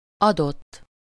Ääntäminen
Ääntäminen Haettu sana löytyi näillä lähdekielillä: unkari Käännös Ääninäyte Adjektiivit 1. given US Adott on sanan ad taipunut muoto.